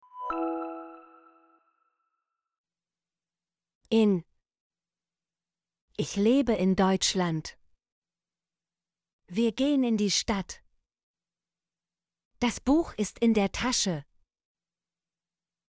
in - Example Sentences & Pronunciation, German Frequency List